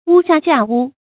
屋下架屋 注音： ㄨ ㄒㄧㄚˋ ㄐㄧㄚˋ ㄨ 讀音讀法： 意思解釋： 比喻機構或文章結構重疊。